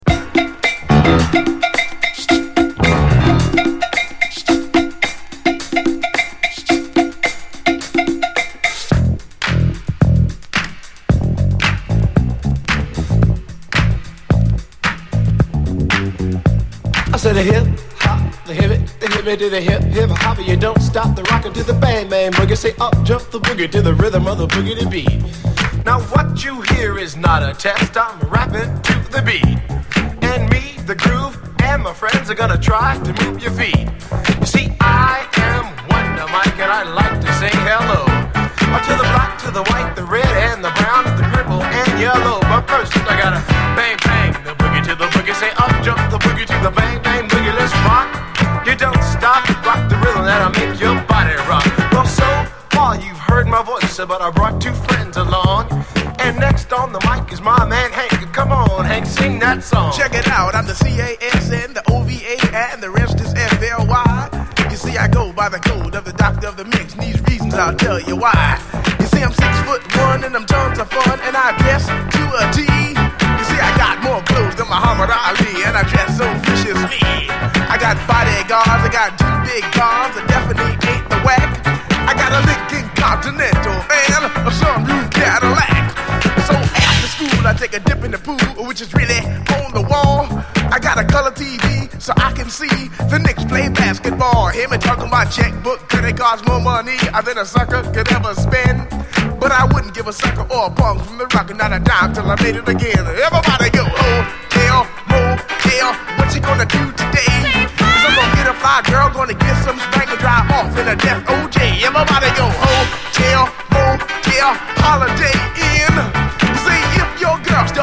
DISCO, HIPHOP, 7INCH
今も新鮮な輝きを放つ、オールドスクール・ディスコ・ラップ金字塔！